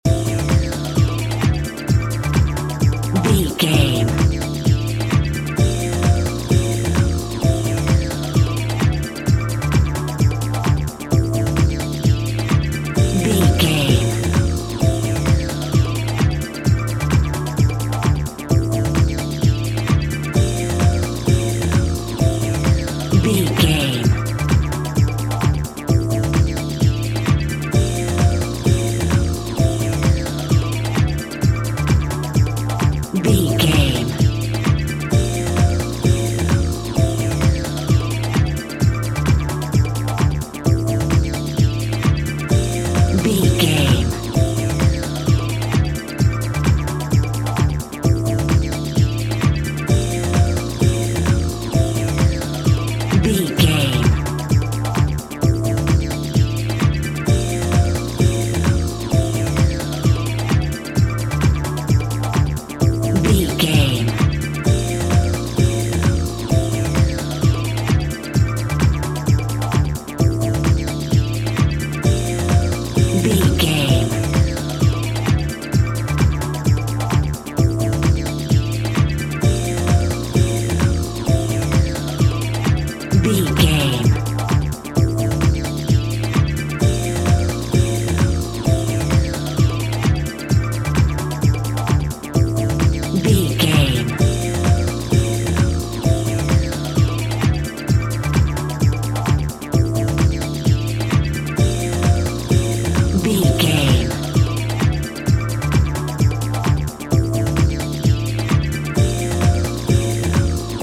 Aeolian/Minor
Fast
groovy
dark
futuristic
synthesiser
drum machine
ambient
electronic
chill out
downtempo
pads
drone
glitch
synth lead
synth bass